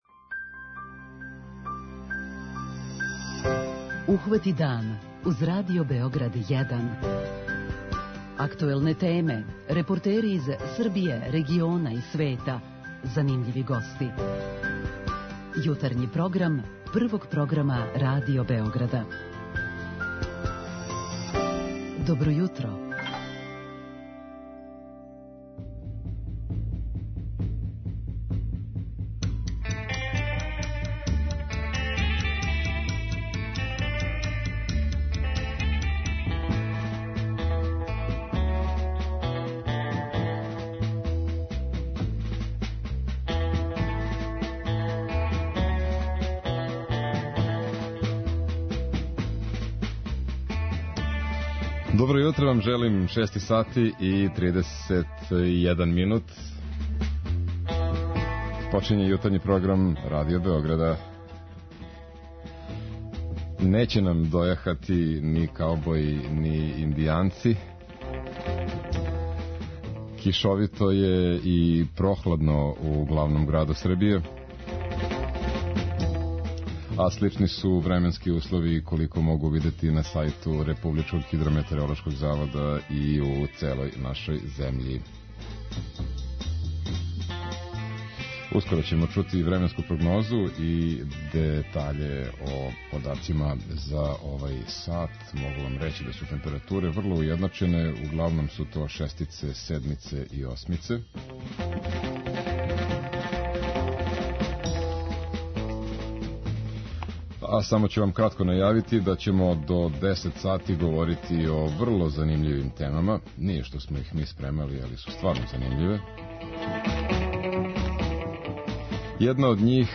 Какви су обичају у Војводини, а какви у Грчкој - рећи ће нам наши дописници с којима ћемо разговарати уживо у Јутарњем програму!